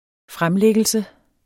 Udtale [ -ˌlεgəlsə ]